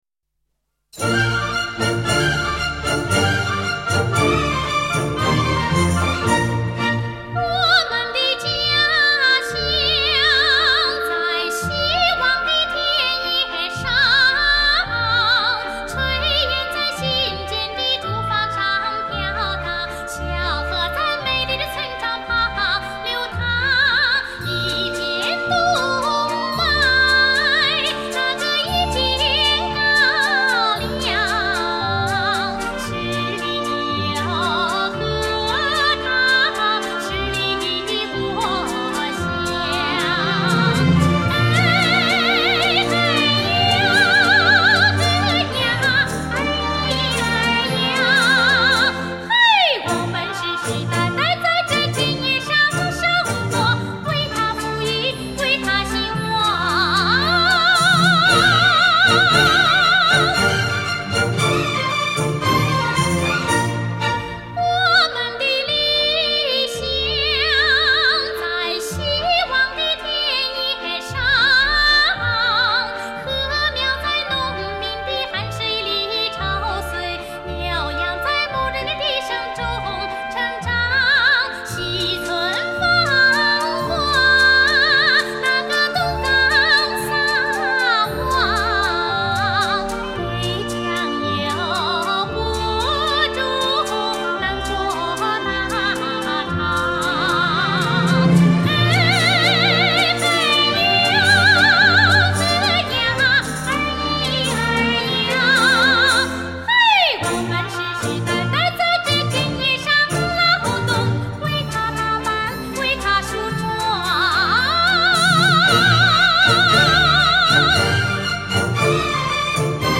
音乐类型：民歌